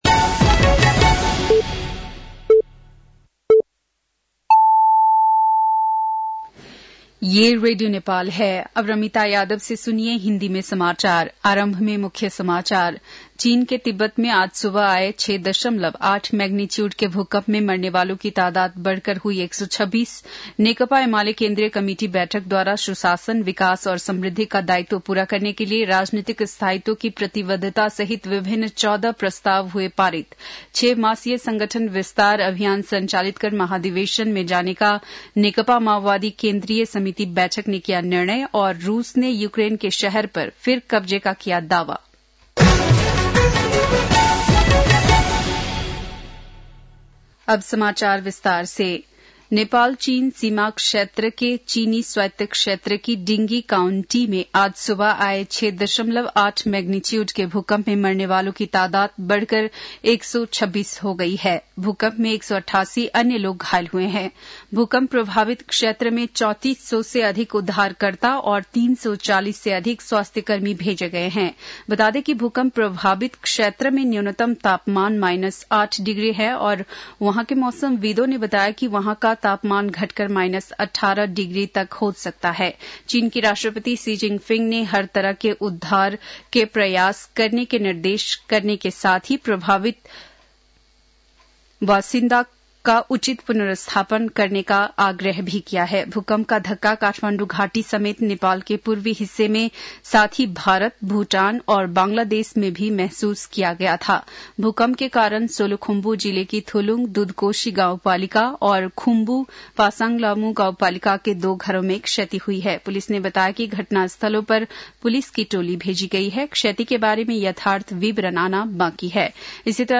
बेलुकी १० बजेको हिन्दी समाचार : २४ पुष , २०८१